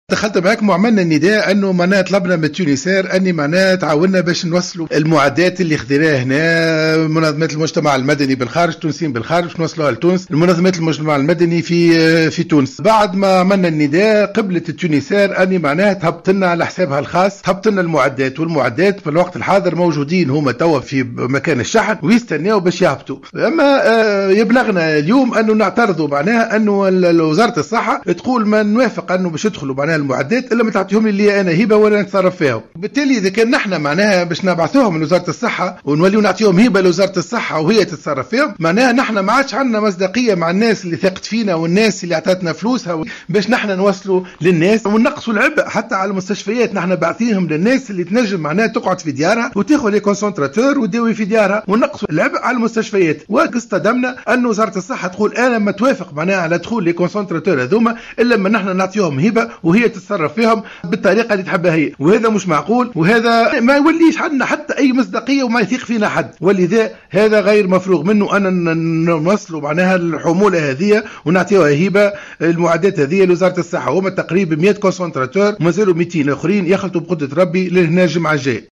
تصريح للجوهرة أف أم